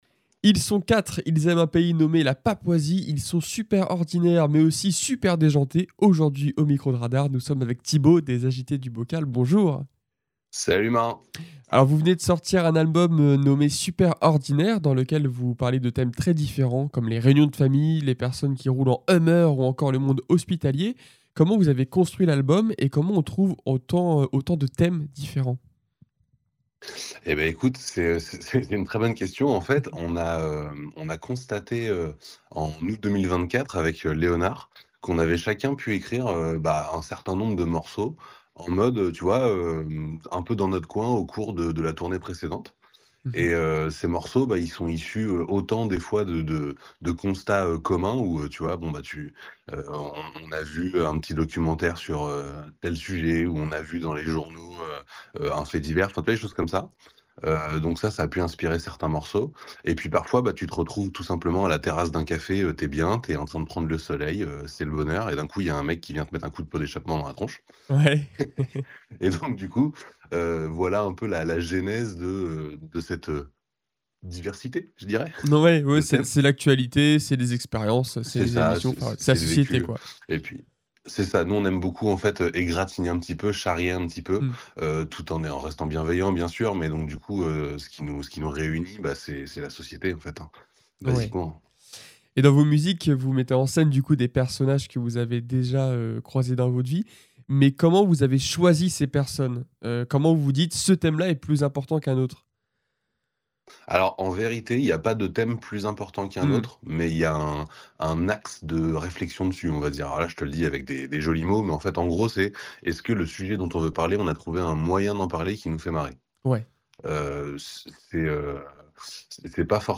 Actu, sport, vie associative et bien plus encore : ici, vous retrouverez toutes les interviews produites par RADAR 🎙 Entre leur tournée, leurs clips et leur nouvel album, Les Agités du Bocal sont passés au micro de Radar pour une interview exclusive !